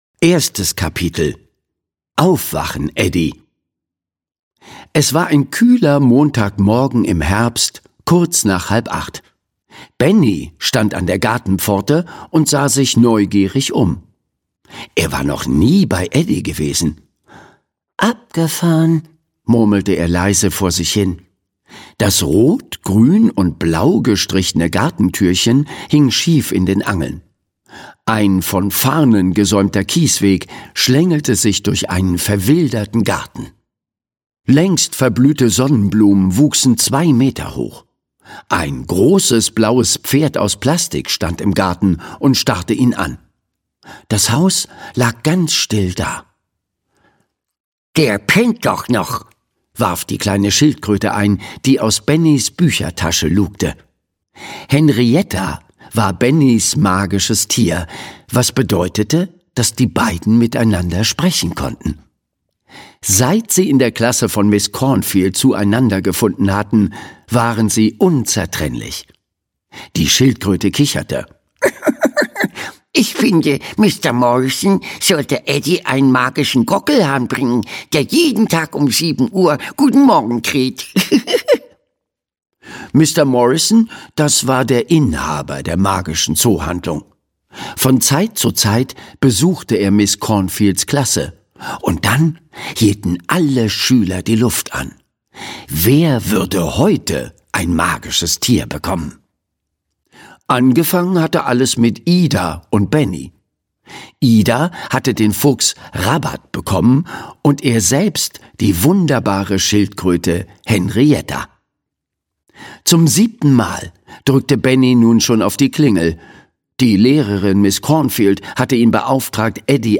Die Schule der magischen Tiere 3: Licht aus! - Margit Auer - Hörbuch